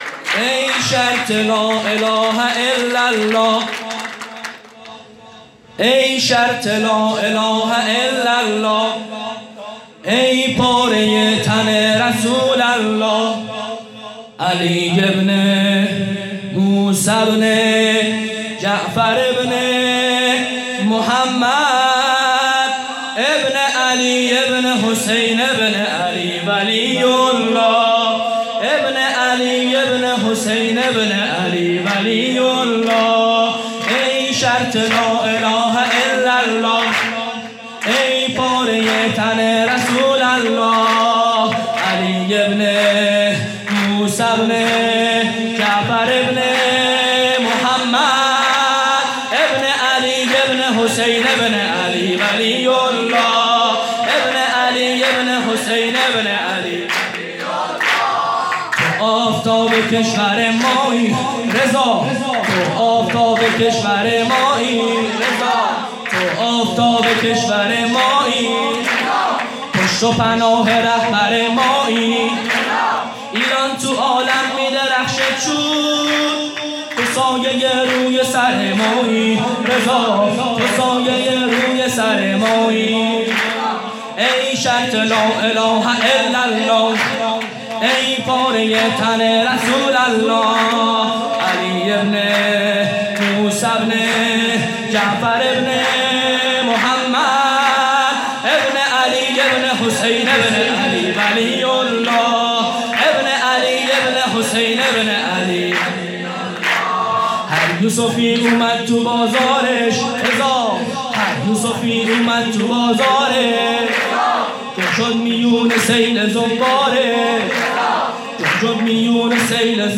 5-سرود-ای-شرط-لااله-الاالله.mp3